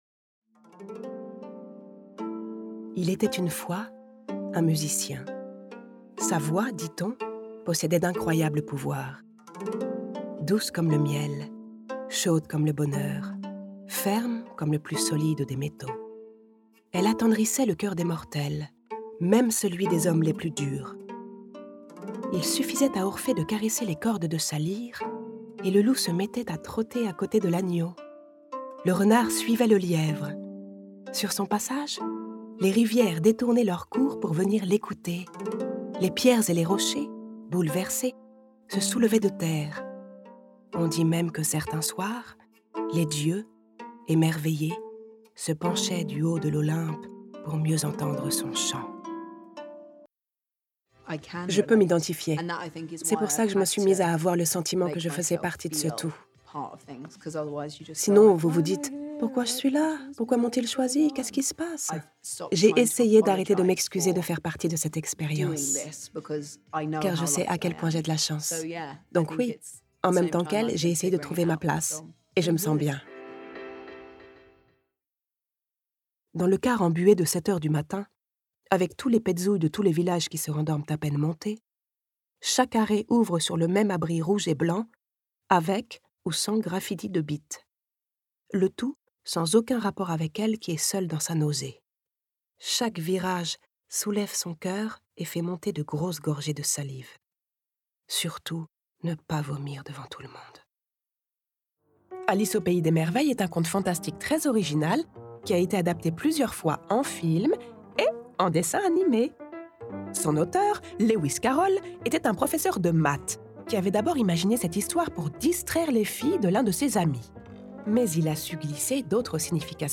Bande démo voix.